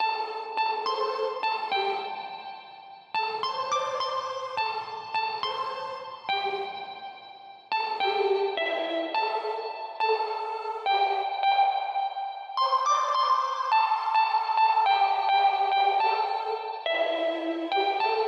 描述：音调：Amin 音律：105bpm 芥末型合成器
标签： 105 bpm Rap Loops Synth Loops 3.08 MB wav Key : A
声道立体声